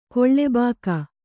♪ koḷḷebāka